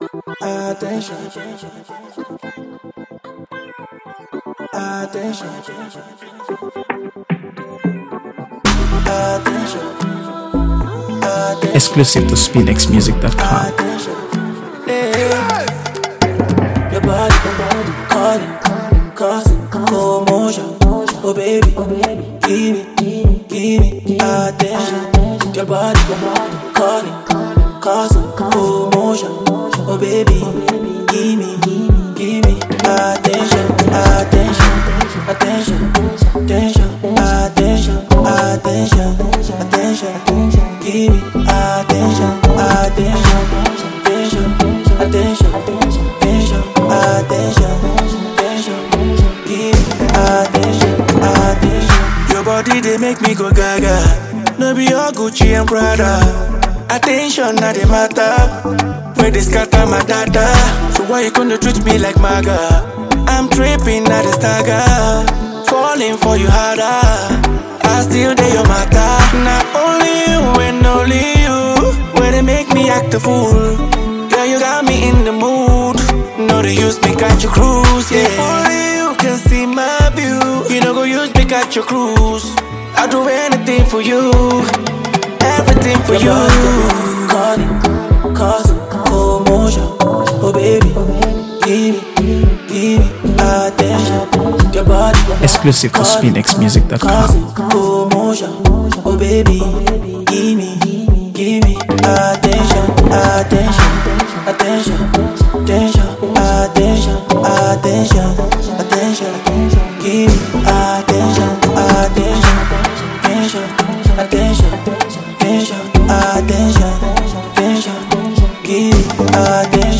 AfroBeats | AfroBeats songs
infectious rhythm and heartfelt lyrics
a fresh blend of Afrobeats and R&B